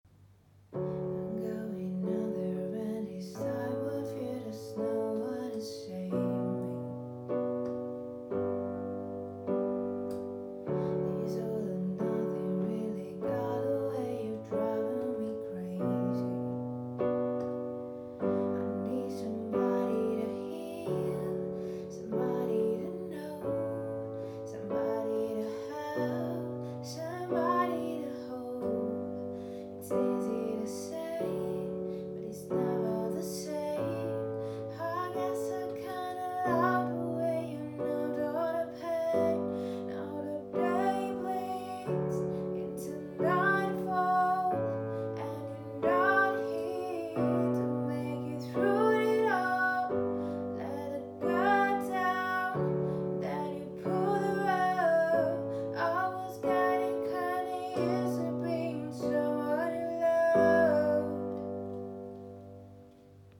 Strimpellamenti notturni